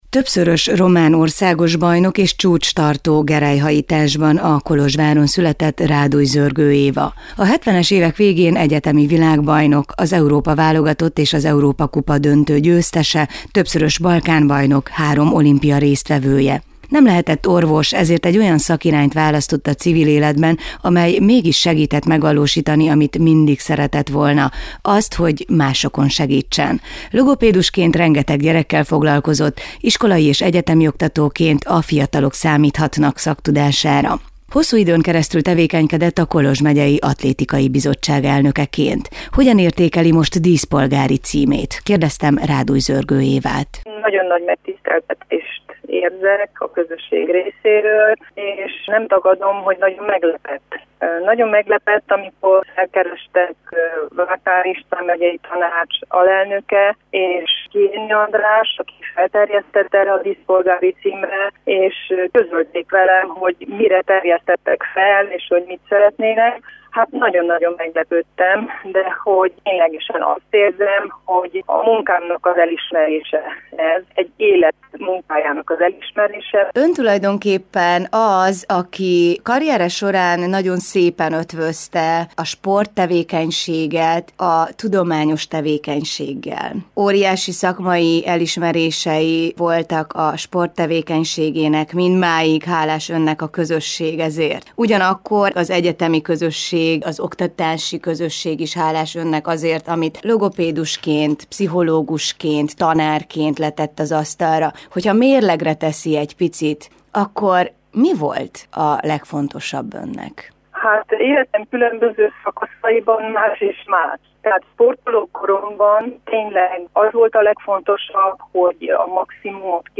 Élő Kolozsvári Rádió